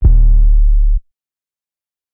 Dro 2 808.wav